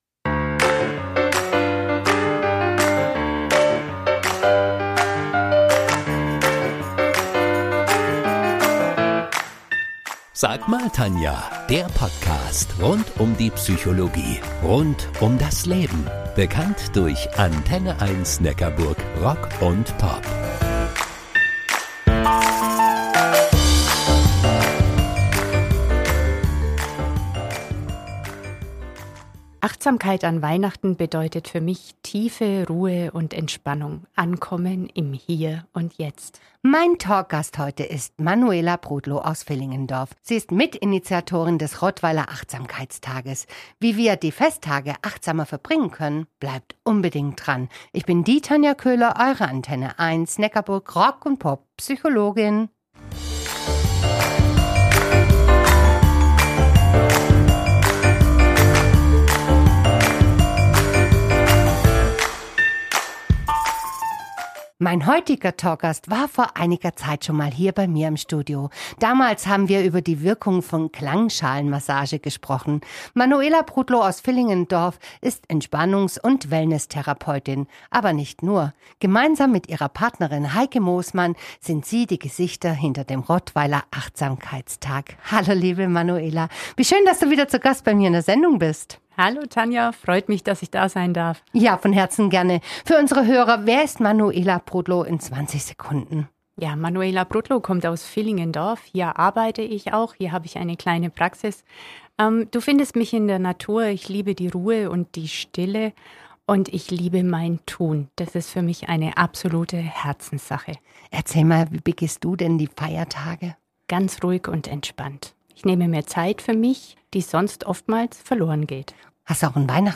Im Gespräch mit Entspannungs- und Wellnesstherapeutin
ACHTUNG! Diese Podcast-Episode ist ein Mitschnitt der